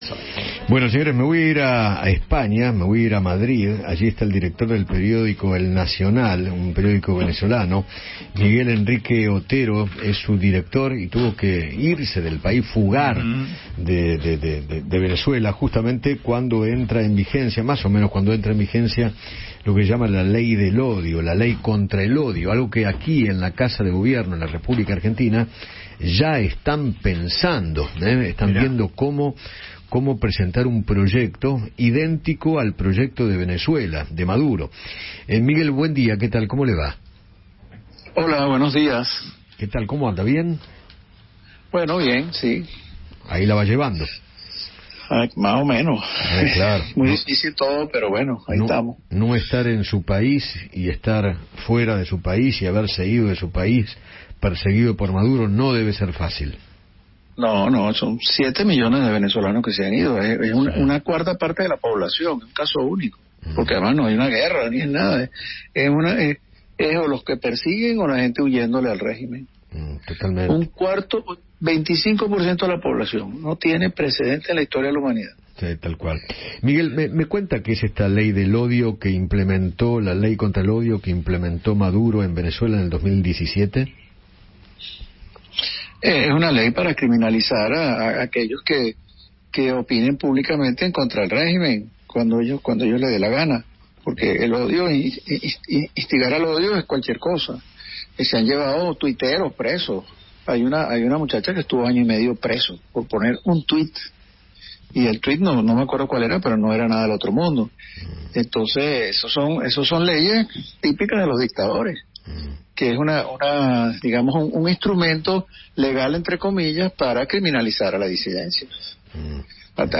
El director del diario venezolano “El Nacional”, Miguel Henrique Otero, conversó con Eduardo Feinmann sobre la implementación de “la ley del odio” en Venezuela, que busca replicar el oficialismo en Argentina, y aseguró que es “un instrumento legal para criminalizar a la disidencia”.